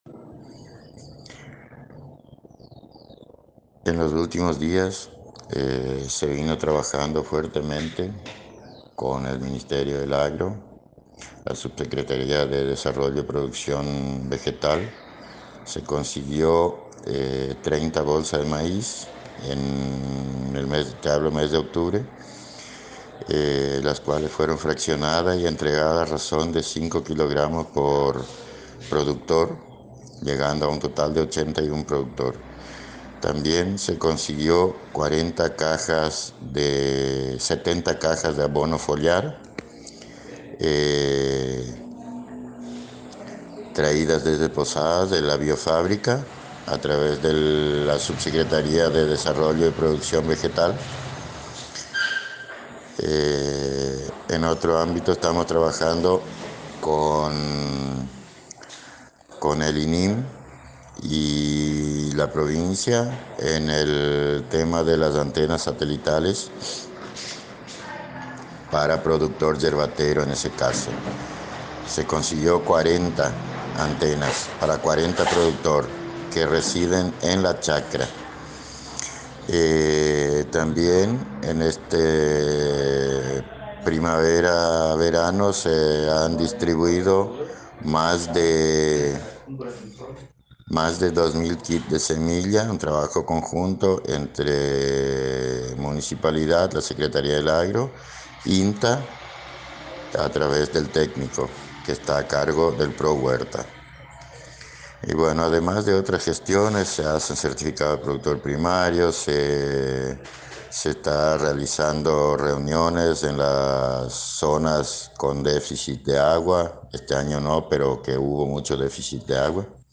Audio: Secretario del Agro Eloy David Berta